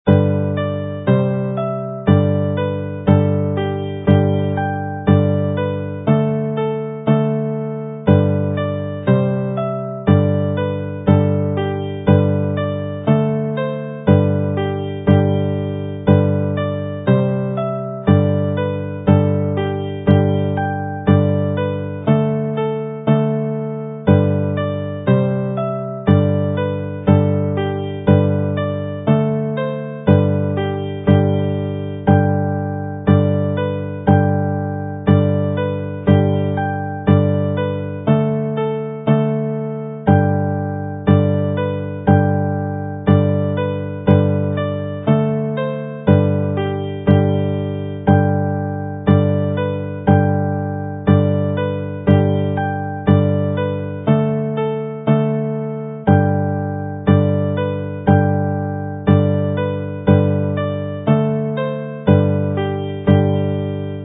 mp3 + cordiau